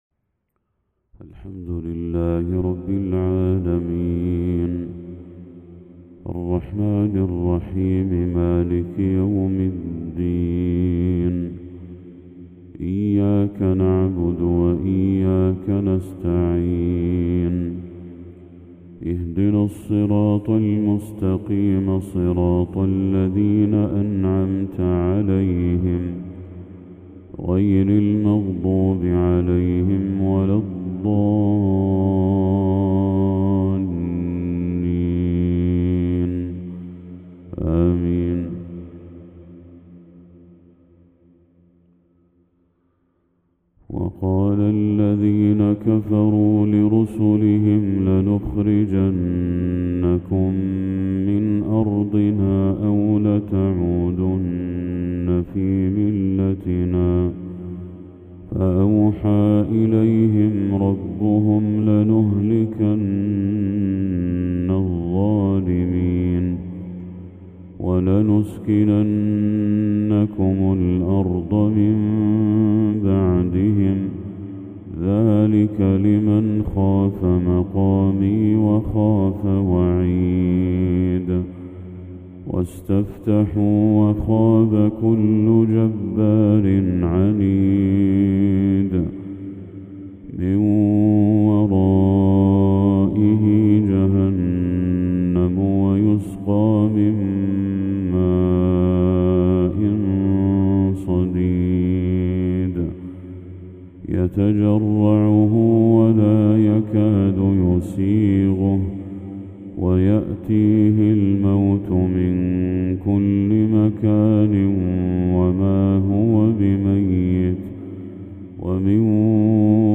تلاوة هادئة من سورة إبراهيم للشيخ بدر التركي | فجر 21 ذو الحجة 1445هـ > 1445هـ > تلاوات الشيخ بدر التركي > المزيد - تلاوات الحرمين